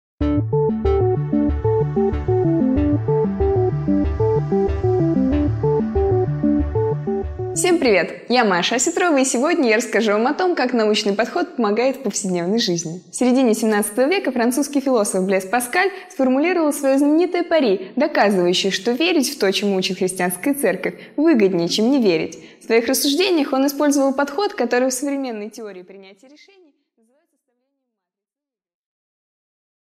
Аудиокнига 5 минут О Западе и Востоке | Библиотека аудиокниг